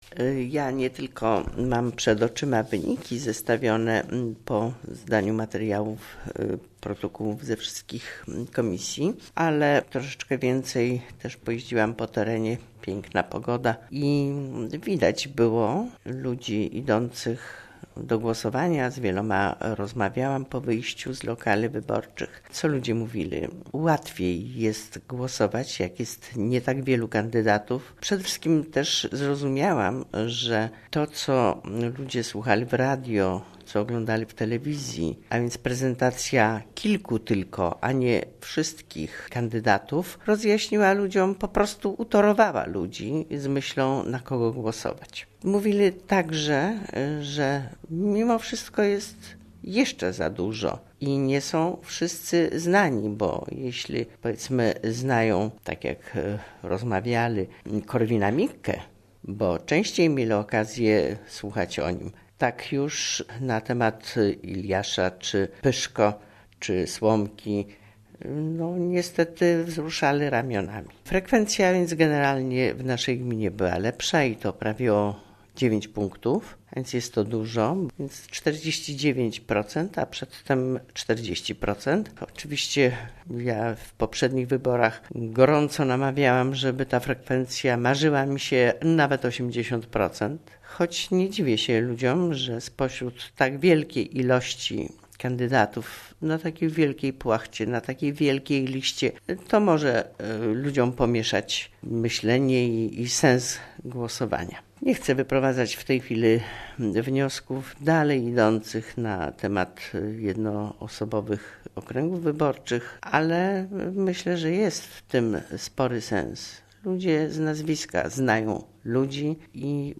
Znane s� ju� wyniki Wybor�w Prezydenckich w znacznej cz�ci Polski. Komentarz do jeszcze nieoficjalnych wynik�w wybor�w:
W�jt Gminy �uk�w Kazimiera Go�awska